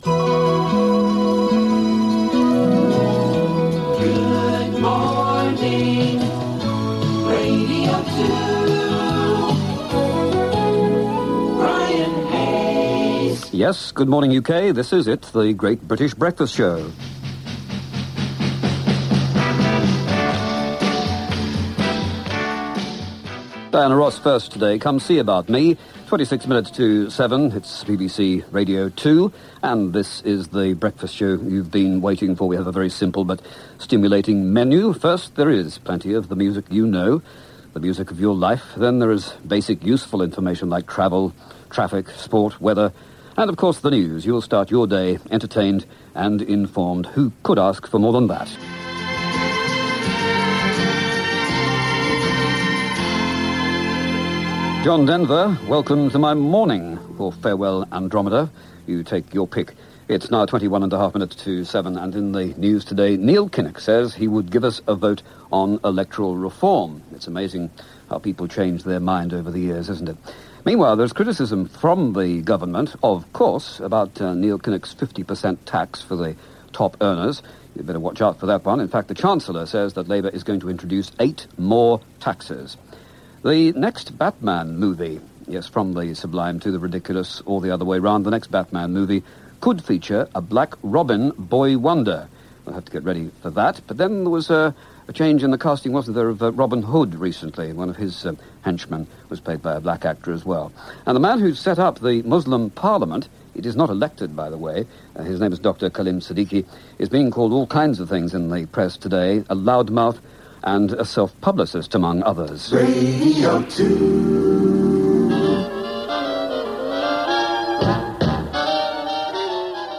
With Derek Jameson moving from Radio 2's breakfast show to a new late-night show co-presented with his wife Ellen, it was Brian Hayes who took over what was still a news-driven show. This is the opening of the first show on Monday 6 January 1992. There are some jingles I'd forgotten about: the re-arranged news jingle and the accapella weather jingle.